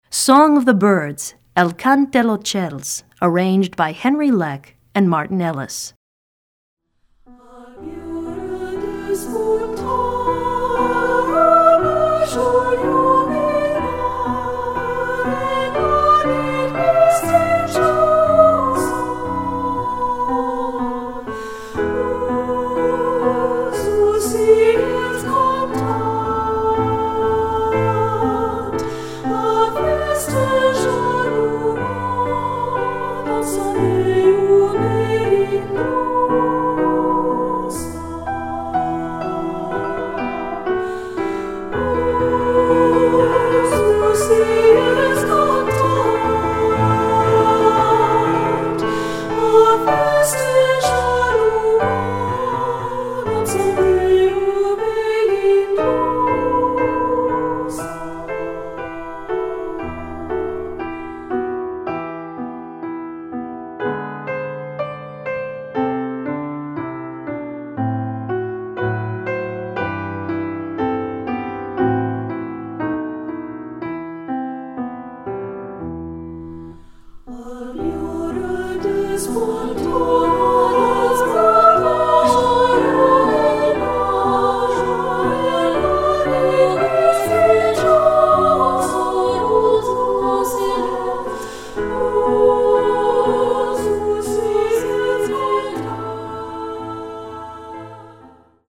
Voicing: 3-Part Treble